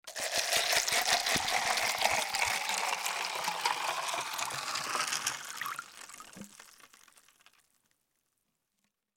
دانلود آهنگ آب 14 از افکت صوتی طبیعت و محیط
دانلود صدای آب 14 از ساعد نیوز با لینک مستقیم و کیفیت بالا
جلوه های صوتی